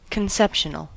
Ääntäminen
Ääntäminen US Haettu sana löytyi näillä lähdekielillä: englanti Käännös Adjektiivit 1. concezionale {m} Määritelmät Adjektiivit Of or relating to conception .